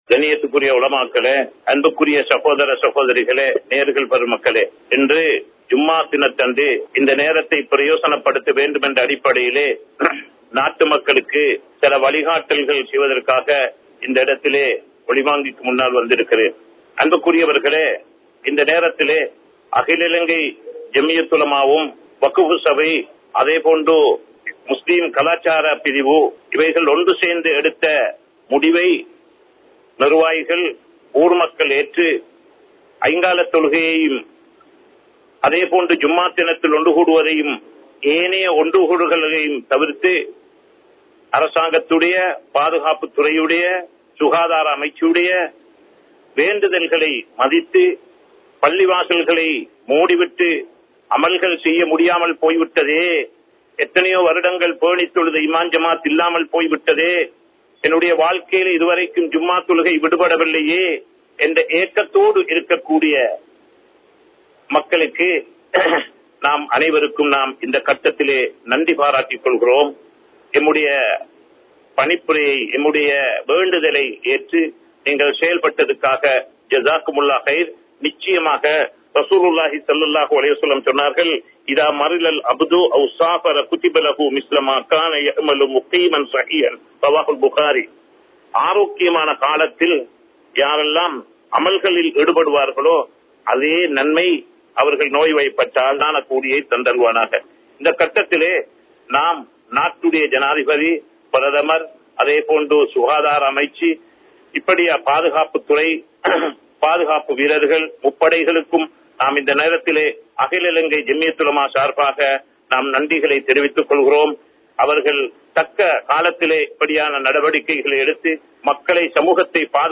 Koorapadum Valihaattalhalai Pin Pattrungal (கூறப்படும் வழிகாட்டல்களை பின்பற்றுங்கள்) | Audio Bayans | All Ceylon Muslim Youth Community | Addalaichenai
Kollupitty Jumua Masjith